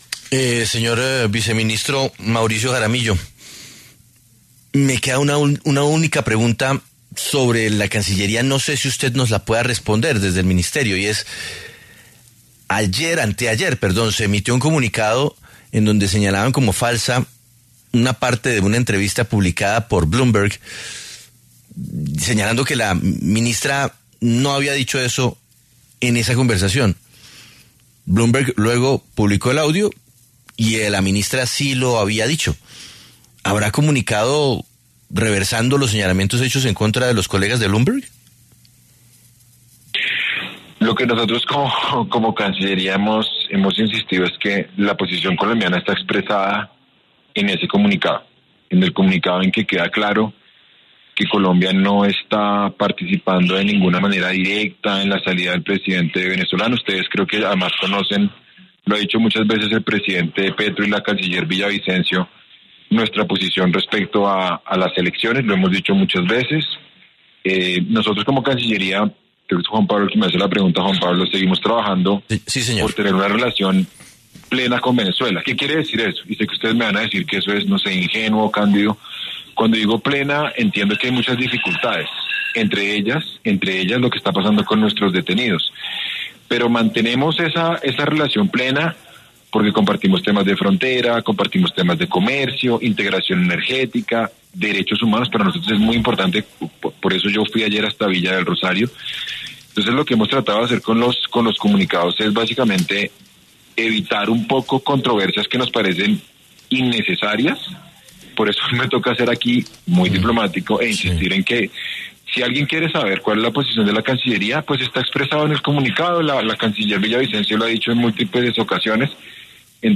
Ante esta situación, el vicecanciller, en conversación con La W, aseguró que ellos, como funcionarios del Ministerio de Relaciones Exteriores, deben ser “cautelosos” con las declaraciones.
Escuche la entrevista con el vicecanciller aquí: